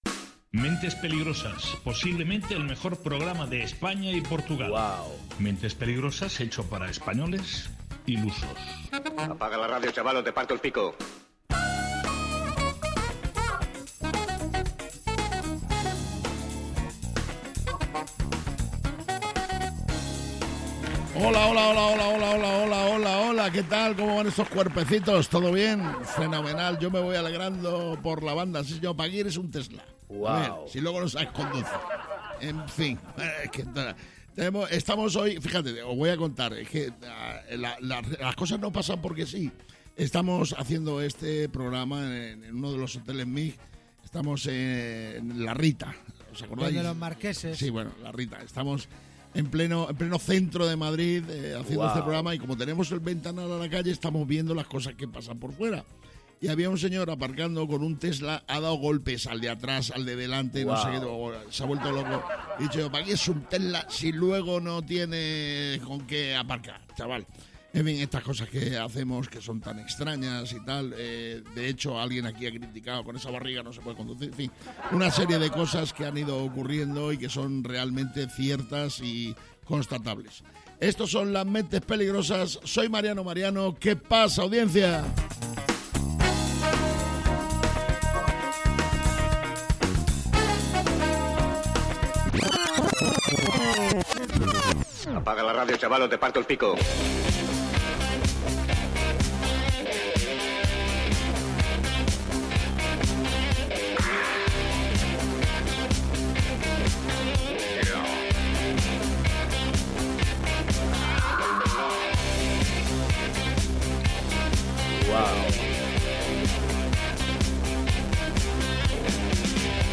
Mentes Peligrosas de Mariano Mariano es un programa de radio en el que cada día se presenta una nueva aventura o no, depende siempre del estado anímico de los participantes en el mismo. Mentes Peligrosas es un programa de radio, esto sí lo tenemos claro, lo que no está tan claro es qué pasará en cada una de sus entregas, no lo saben ni los que lo hacen, ni sus propios entornos.